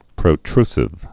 (prō-trsĭv, prə-)